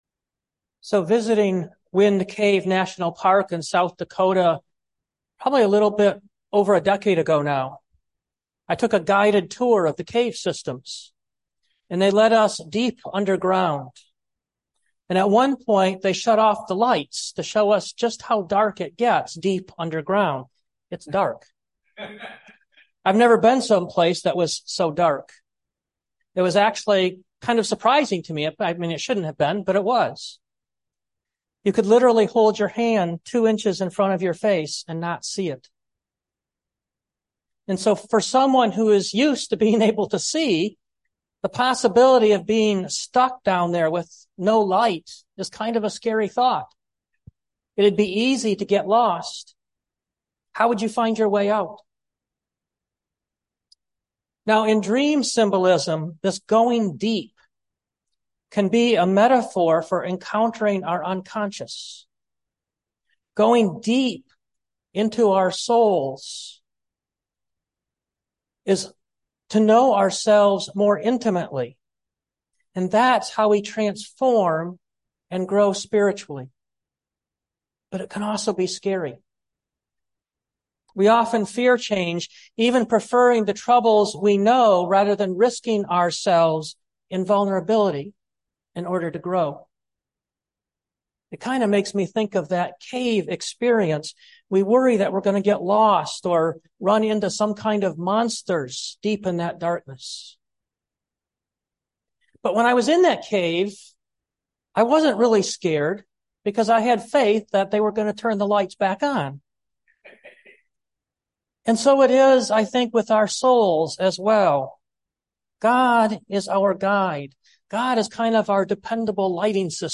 2023 Going Deeper Preacher